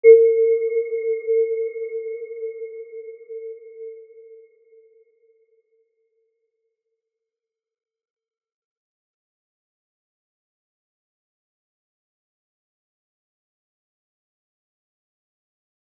Aurora-C5-mf.wav